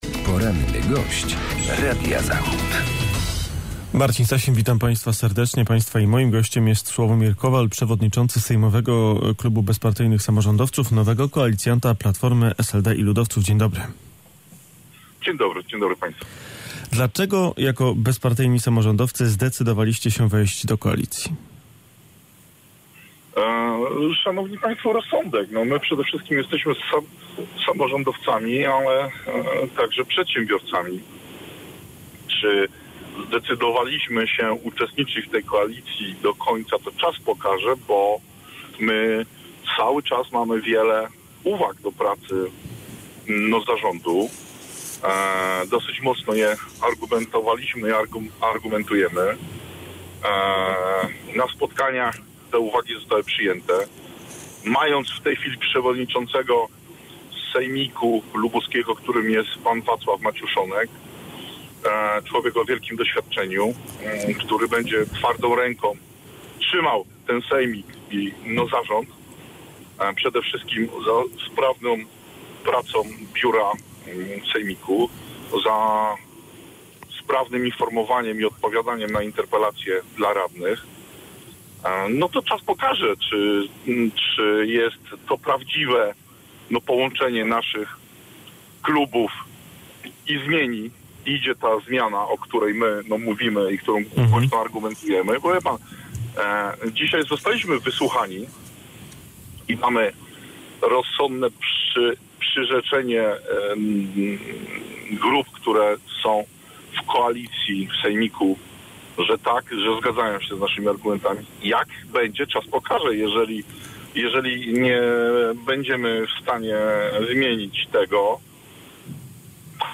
Sławomir Kowal, radny lubuski (Bezpartyjni Samorządowcy)